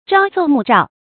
朝奏暮召 注音： ㄔㄠˊ ㄗㄡˋ ㄇㄨˋ ㄓㄠˋ 讀音讀法： 意思解釋： 指早晨上書帝王，晚上就被召見。